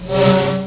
TITANIC - hør skipets stemme 90 år etter!
titanichorn.wav